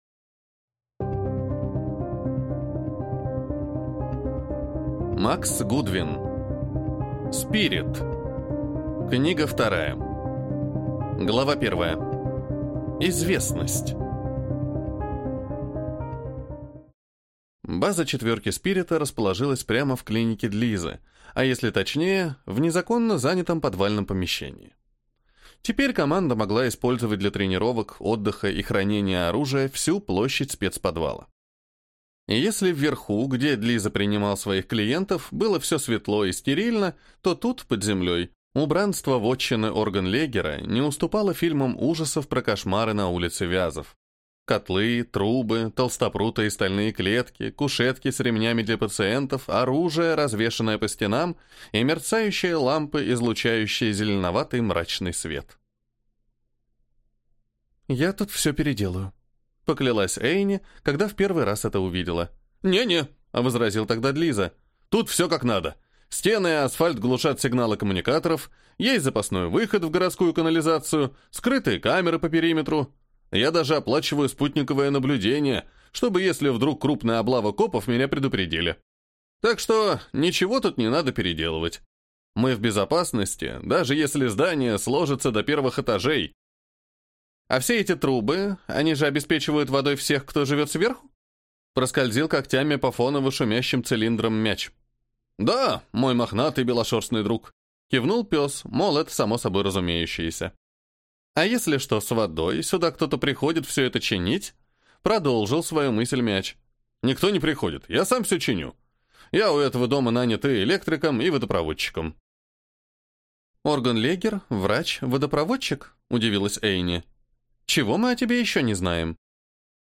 Аудиокнига СПИРИТ. Книга 2 | Библиотека аудиокниг
Прослушать и бесплатно скачать фрагмент аудиокниги